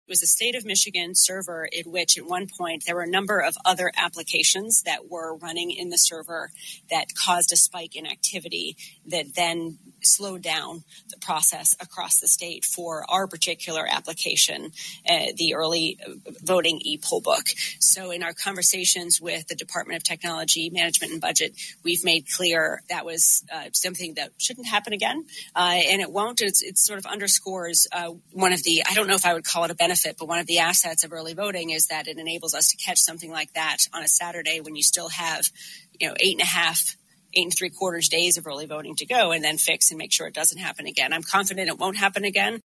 July 29, 2024; Lansing, MI, USA; Michigan Secretary of State Jocelyn Benson discussed early voting turnout during a press briefing. (YouTube image courtesy State of Michigan)